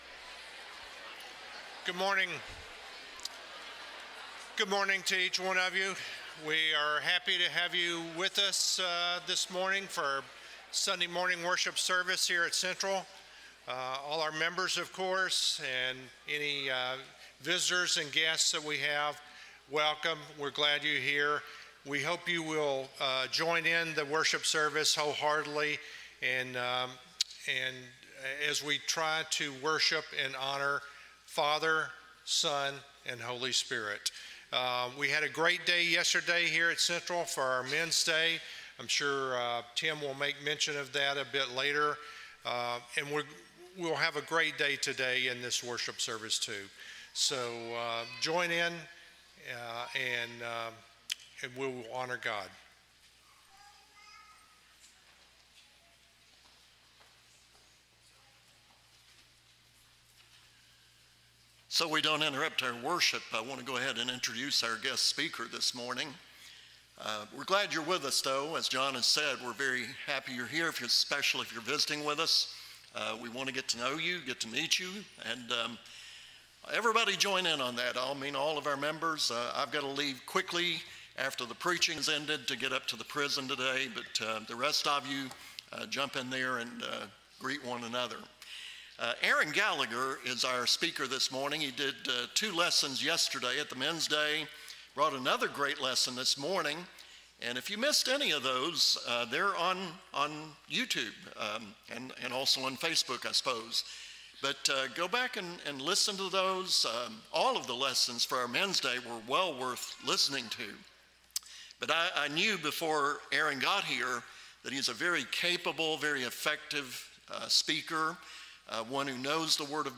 Acts 22:16 English Standard Version Series: Sunday AM Service